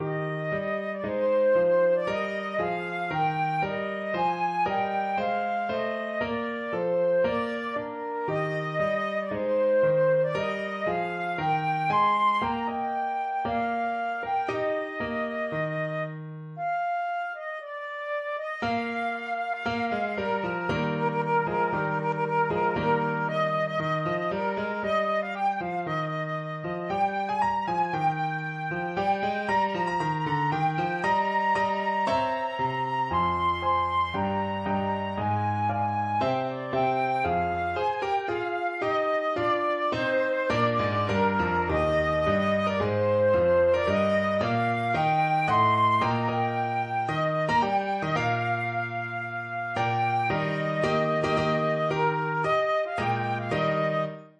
Traditional Trad. Men of Harlech (Traditional Welsh) Flute version
Flute
March = c. 116
Eb major (Sounding Pitch) (View more Eb major Music for Flute )
4/4 (View more 4/4 Music)
Bb5-C7
Traditional (View more Traditional Flute Music)